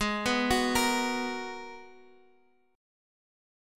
Abm9 chord